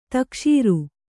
♪ takṣīru